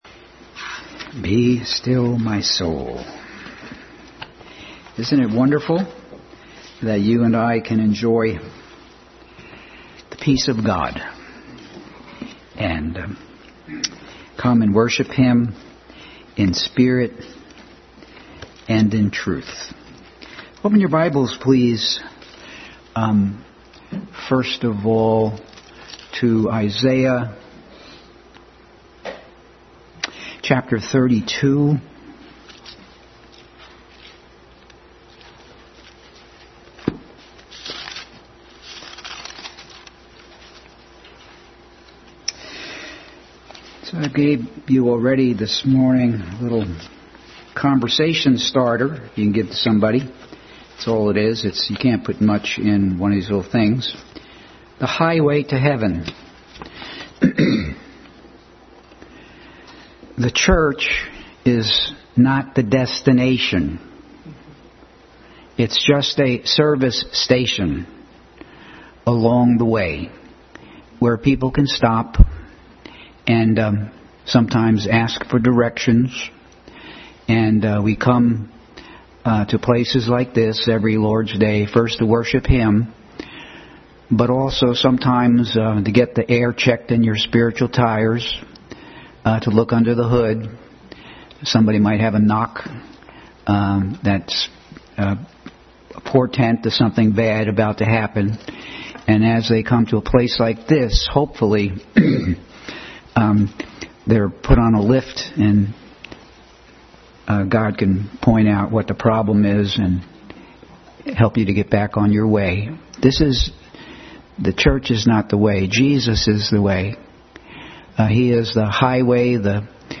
Isaiah 32 Passage: Isaiah 32, 2 Peter 1:5, Isaiah 66:2, Deuteronomy 8:2, Psalm 86:1 Service Type: Family Bible Hour